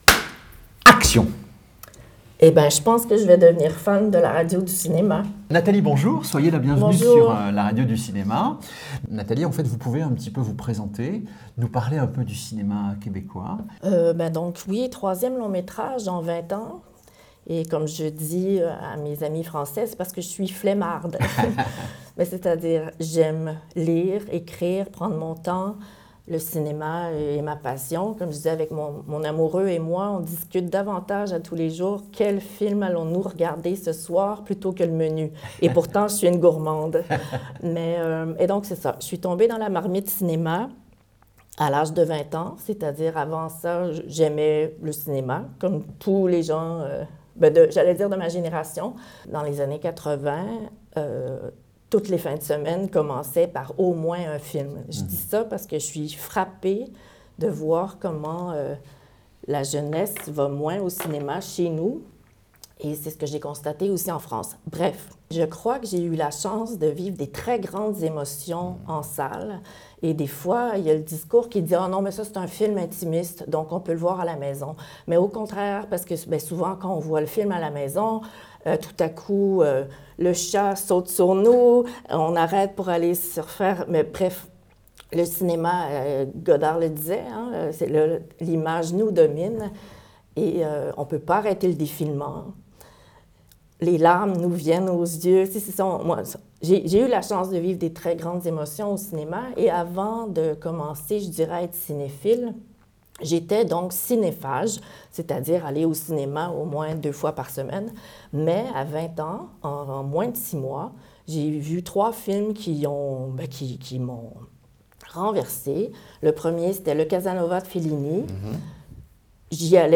Ce long-métrage, qui explore les thèmes de l'émancipation et de la quête d'identité, est un véritable hymne à la liberté. Dans cet entretien